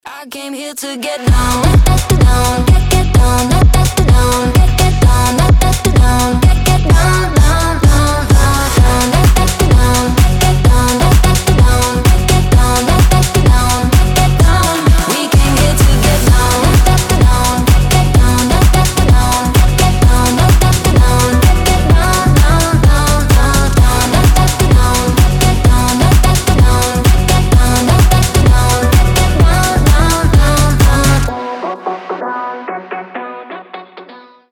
• Качество: 320, Stereo
заводные
Dance Pop
house